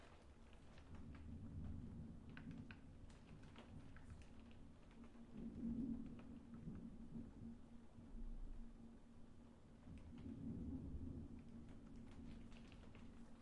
暴风雨 " 暴风雨封闭窗
描述：用一对Neumann KM76通过Manley Dual Mono Mic Pre和Lavry Blue AD录制的闭窗照明风暴。
Tag: 大气 封闭 室内装饰 天气 窗口